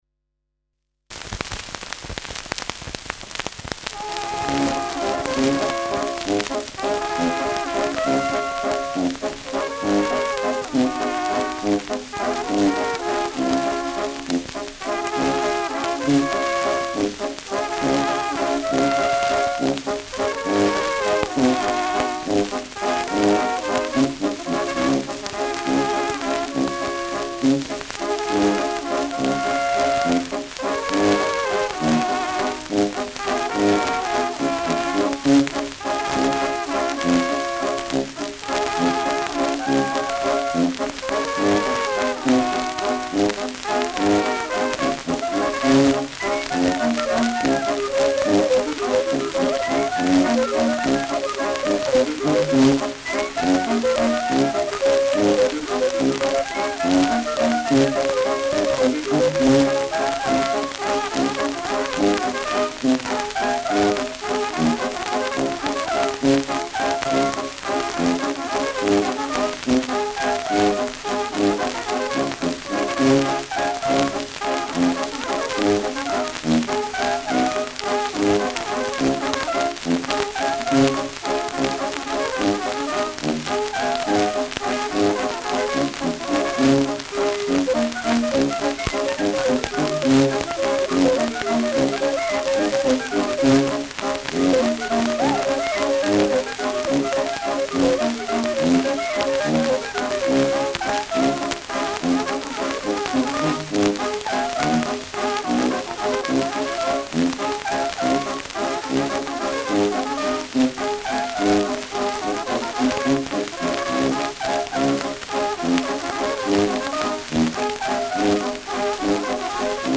Trompetenländler
Schellackplatte